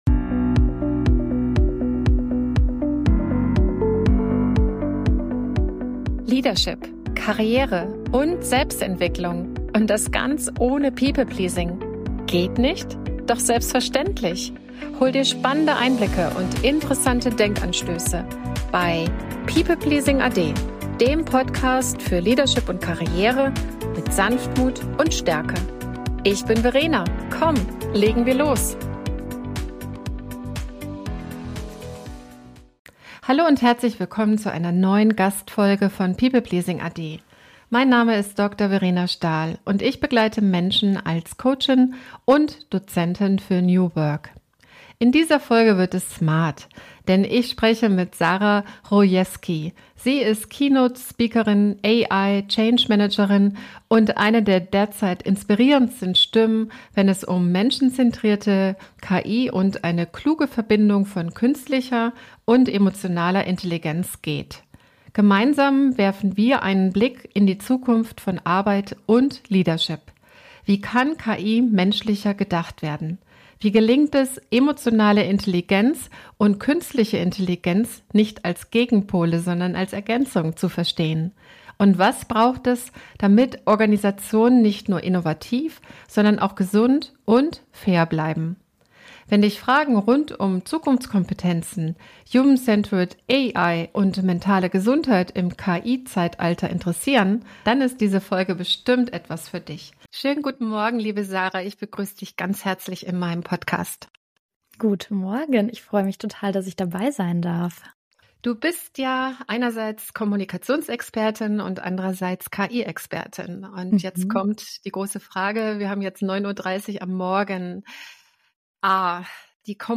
Ein inspirierender Dialog über Chancen, Verantwortung und eine neue Arbeitskultur.